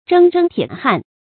铮铮铁汉 zhēng zhēng tiě hàn
铮铮铁汉发音